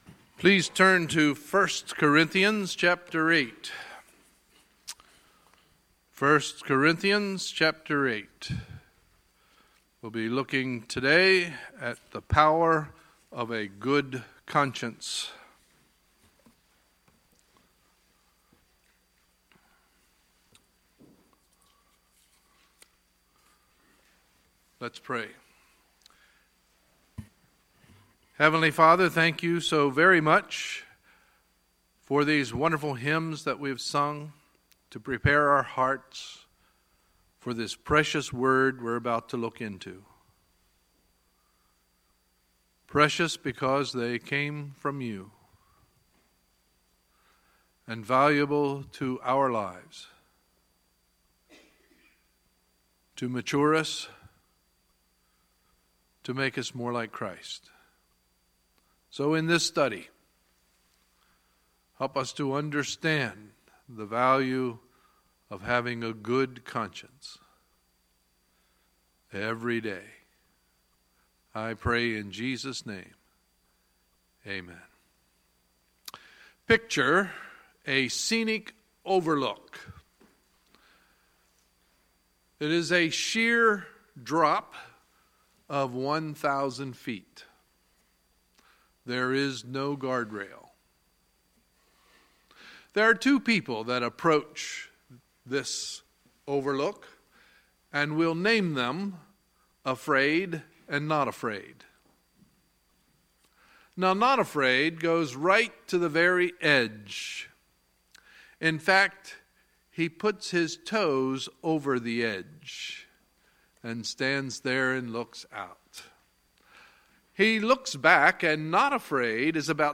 Sunday, March 19, 2017 – Sunday Morning Service